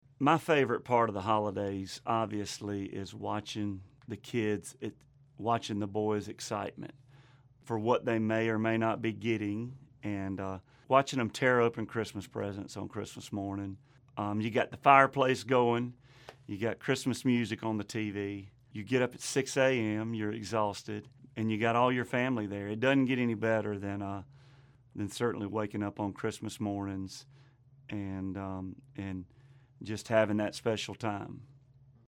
Audio / LUKE BRYAN TALKS ABOUT HIS FAVORITE PART OF CHRISTMAS.